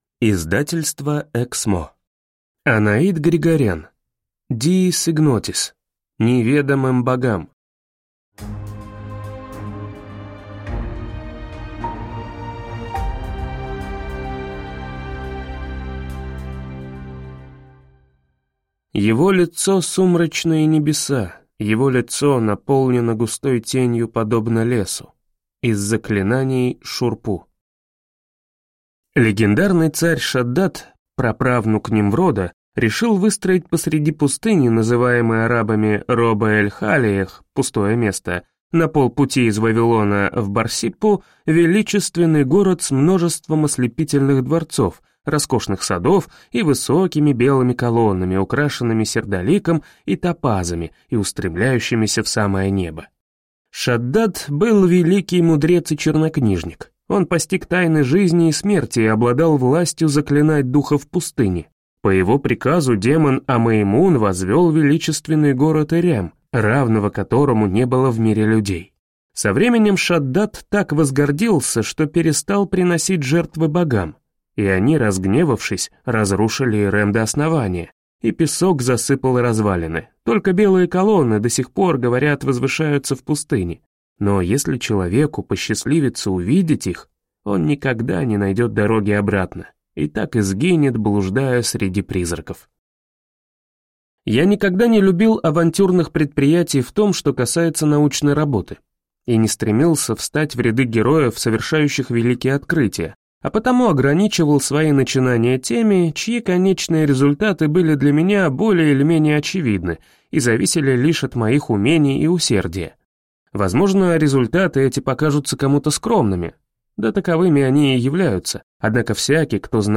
Аудиокнига Неведомым богам | Библиотека аудиокниг